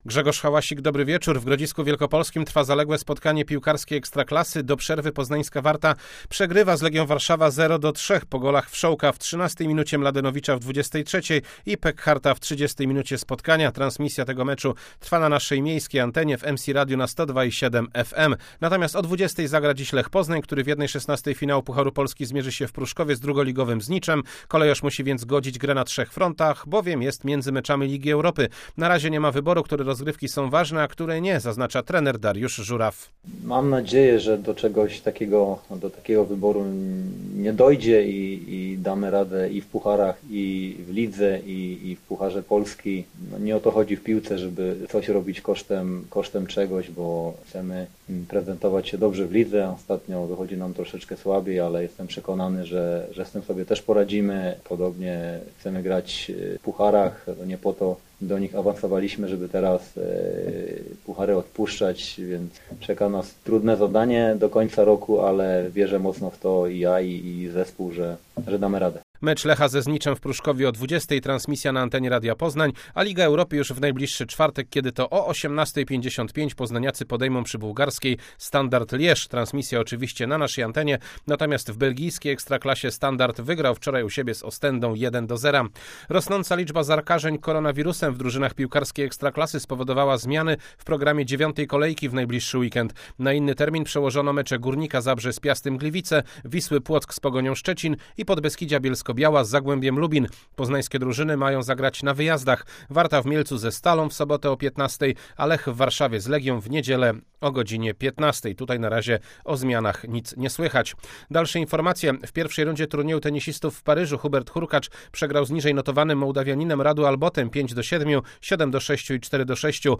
02.11. SERWIS SPORTOWY GODZ. 19:05